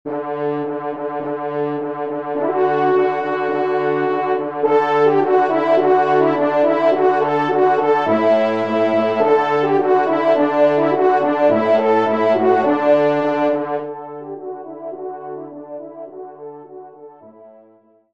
Genre :  Divertissement pour Trompe ou Cor et Piano
4e Trompe